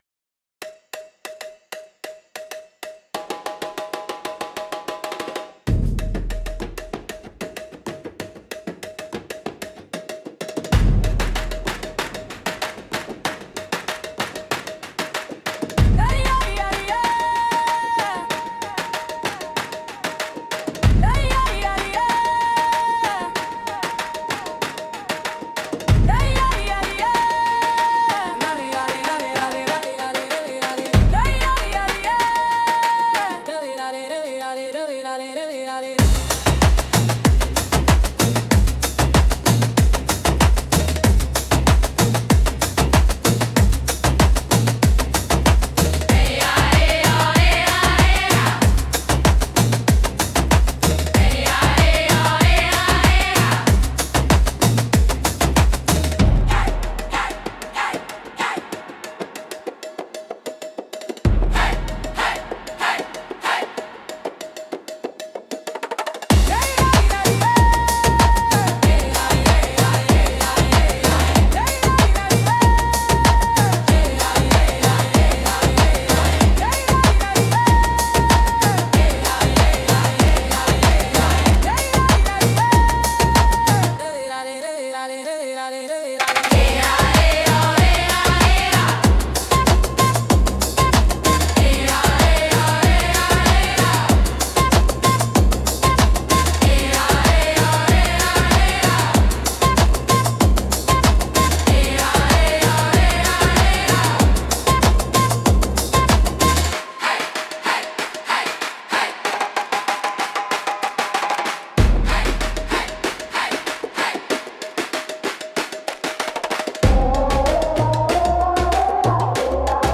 迫力と神秘性が共存するジャンルです。